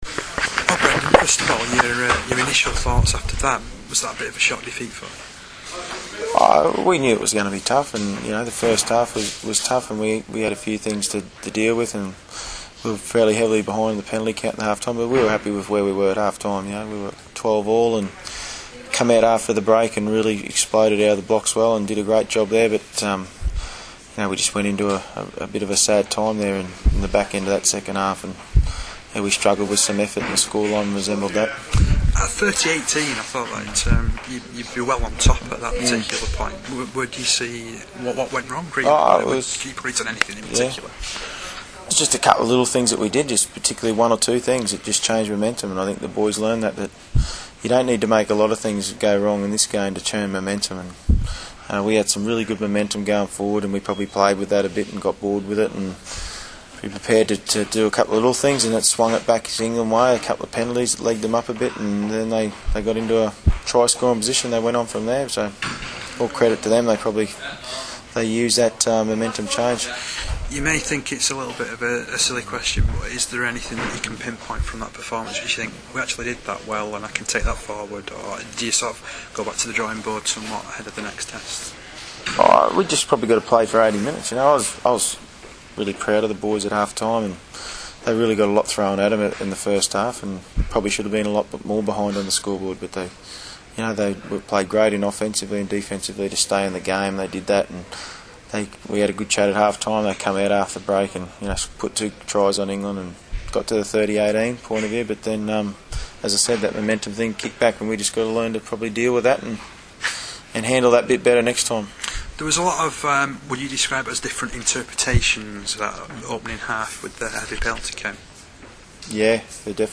POST TEST INTERVIEW (.mp3)